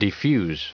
Prononciation du mot defuse en anglais (fichier audio)
Prononciation du mot : defuse